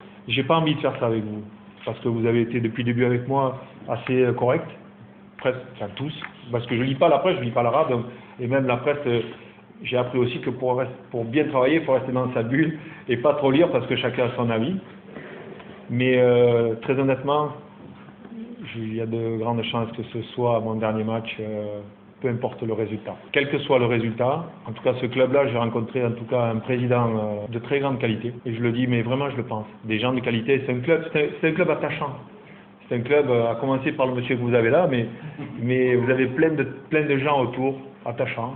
اعلن المدرب الفرنسي جوزي أنيقو في ندوة صحفية عشية اليوم الخميس أن مباراة الاهلي ستكون اخر مباراة له على راس الترجي الرياضي التونسي.